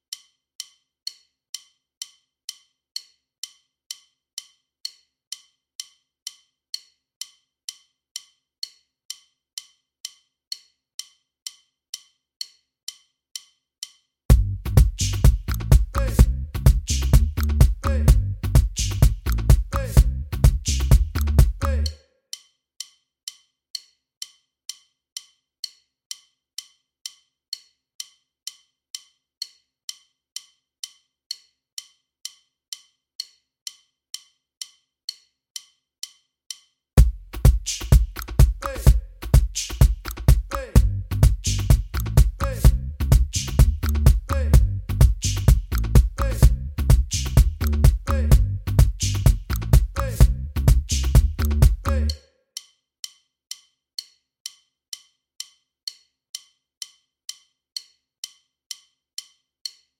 Minus Main Guitar For Guitarists 2:58 Buy £1.50